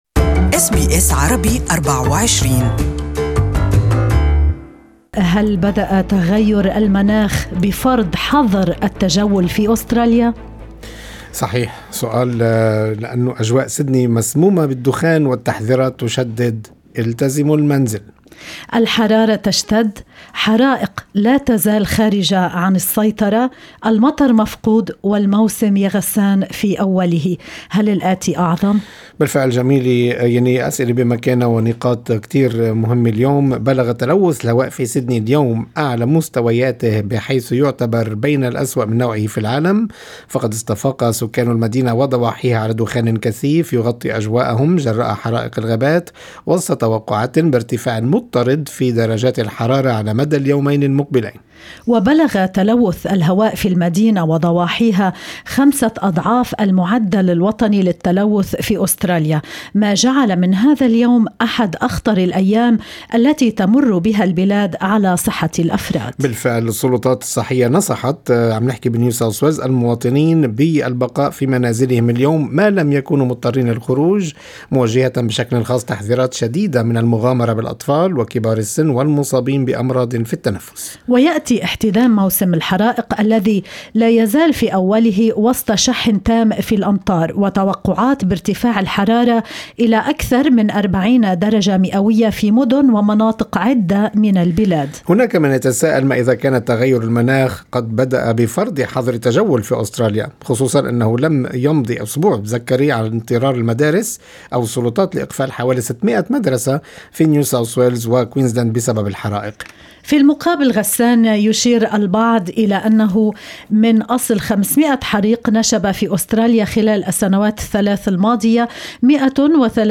يمكن الاستماع إلى الحديث الكامل معه بالضغط على الرابط أعلاه.